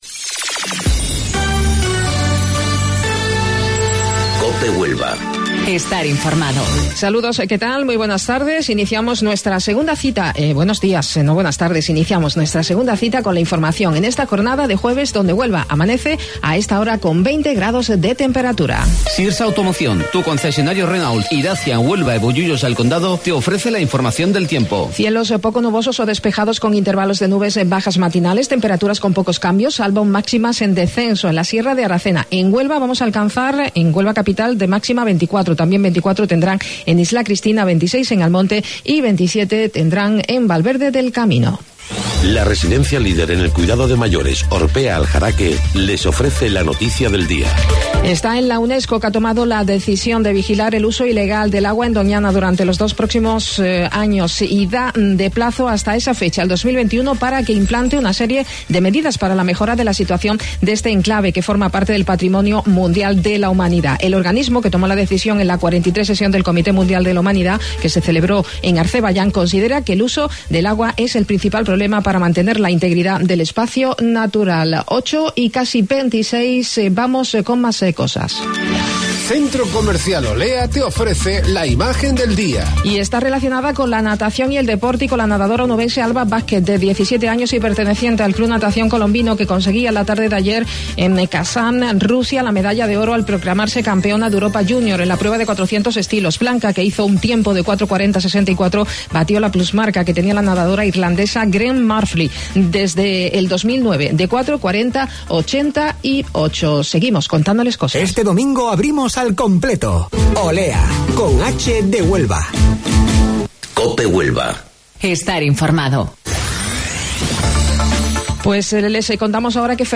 AUDIO: Informativo Local 08:25 del 4 de Julio